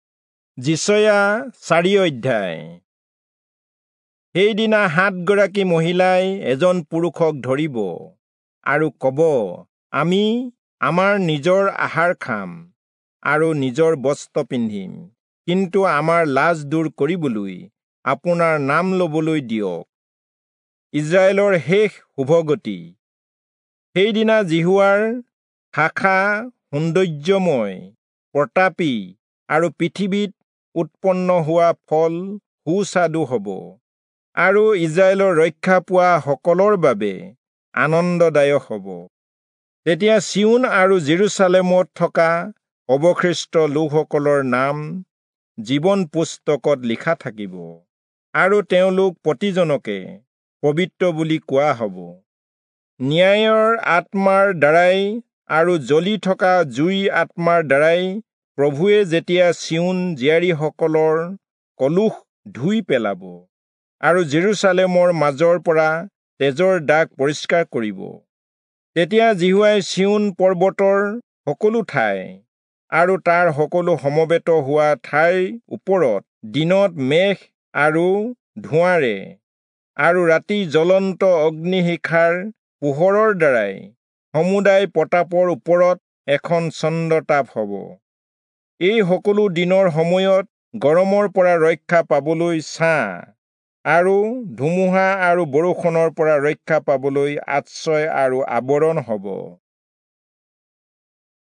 Assamese Audio Bible - Isaiah 51 in Tov bible version